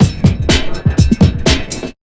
NYC125LOOP-R.wav